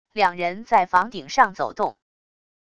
两人在房顶上走动wav音频